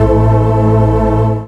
orgTTE54030organ-A.wav